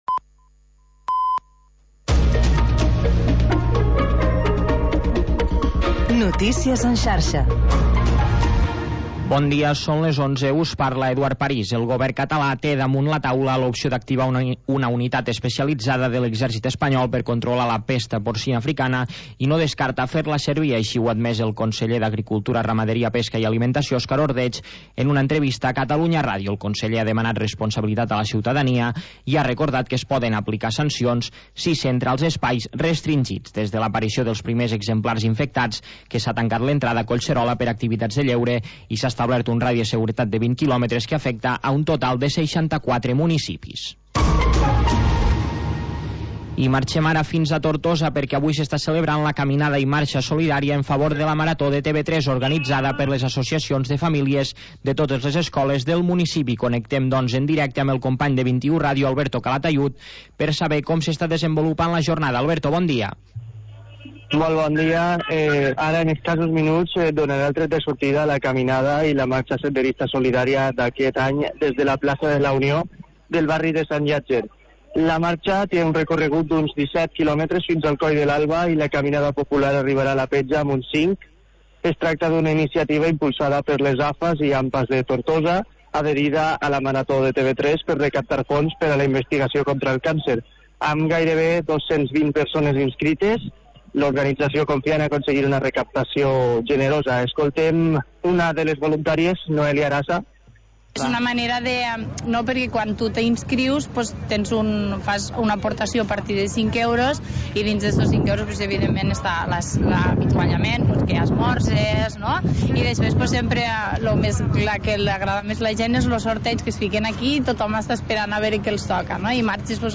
Programa variat amb format de magazín amb seccions relacionades amb l'havanera i el cant de taverna.